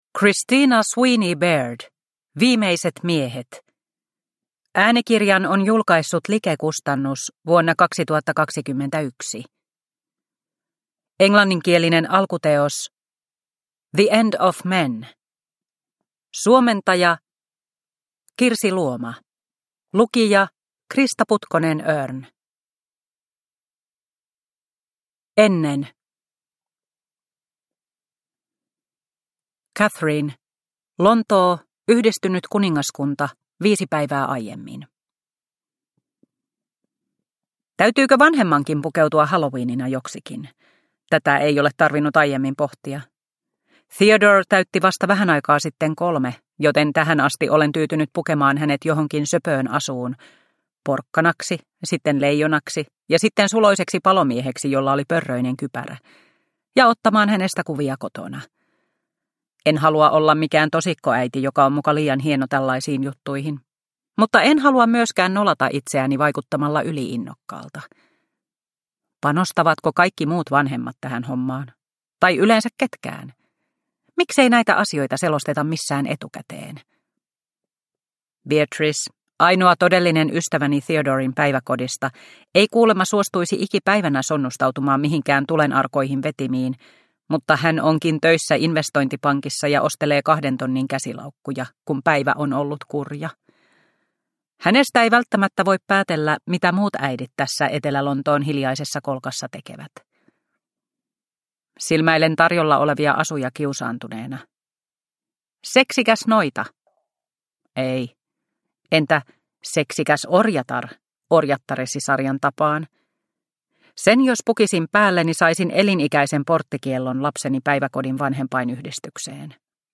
Viimeiset miehet – Ljudbok – Laddas ner